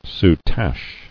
[sou·tache]